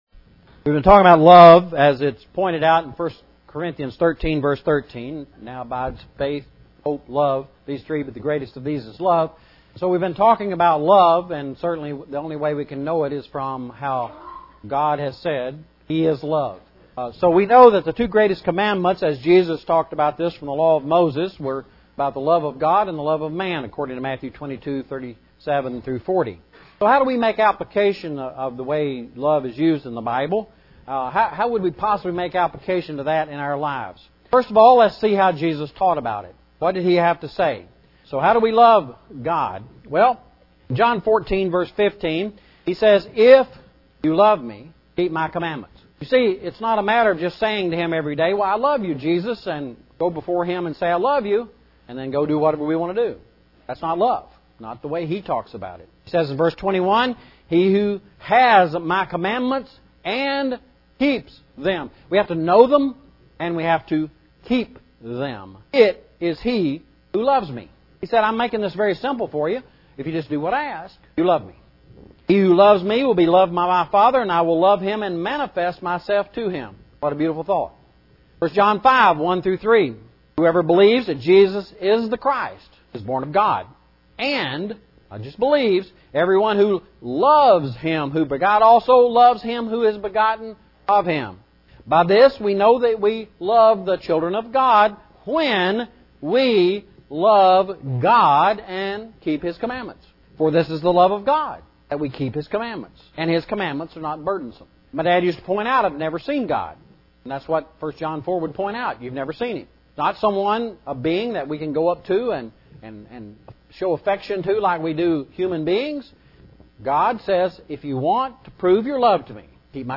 Recording of both lessons are below, followed by a gallery of the PowerPoint slides.